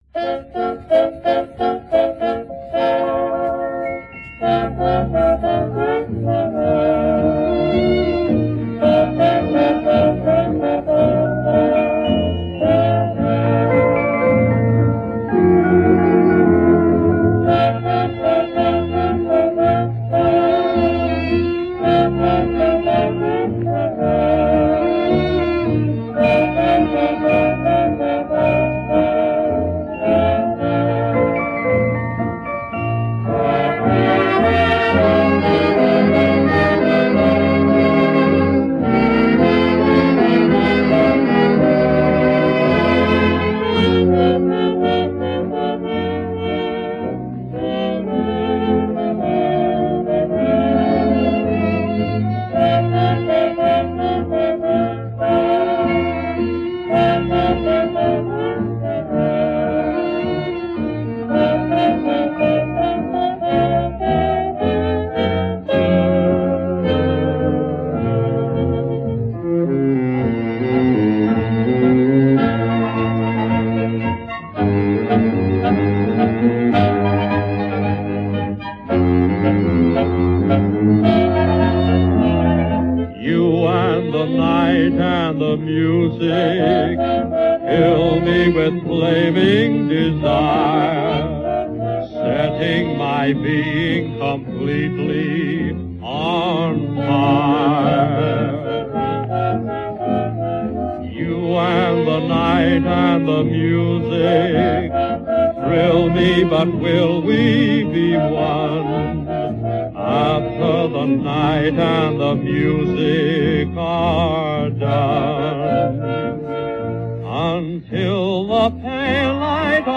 оркестра